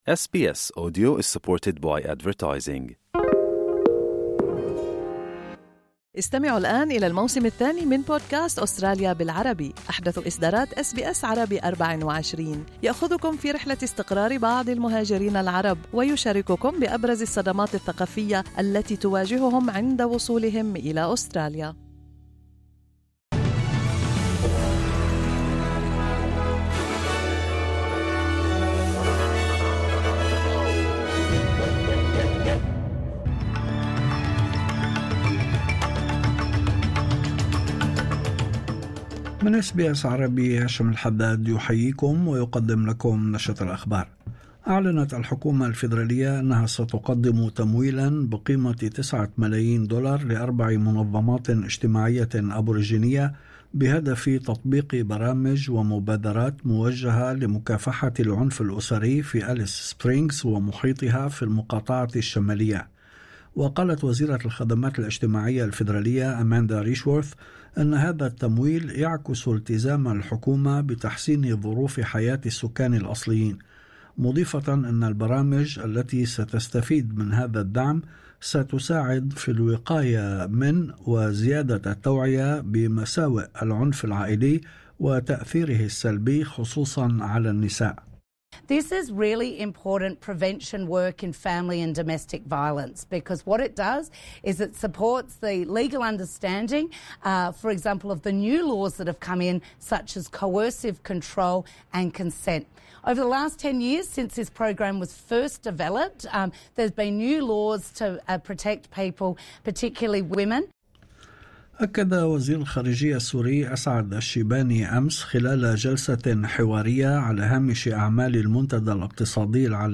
نشرة أخبار الظهيرة 23/01/2025